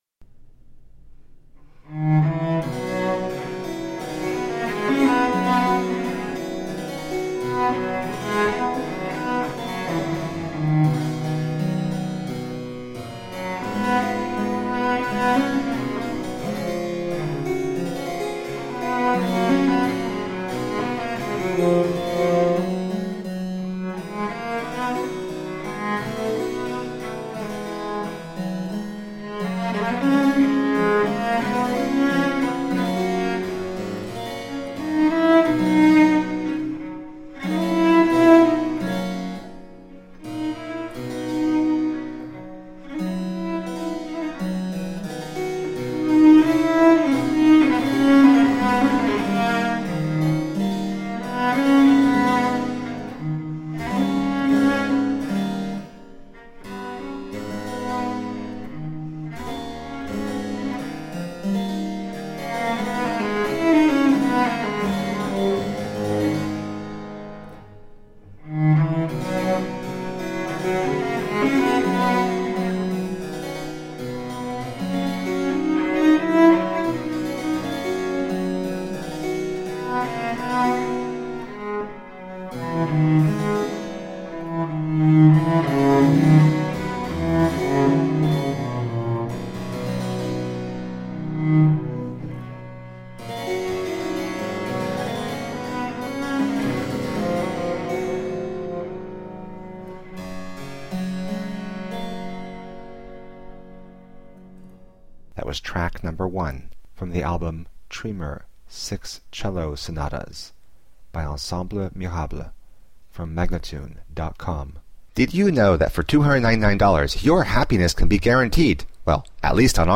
Rare and extraordinary music of the baroque.
Classical, Baroque, Instrumental
Harpsichord